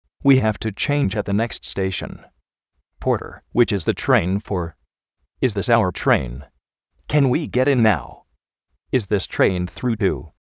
zrú tréin
exprés tréin
fast tréin
míxt tréin
slóu tréin
méil tréin